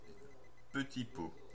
Ääntäminen
US Tuntematon aksentti: IPA : /dʒɑr/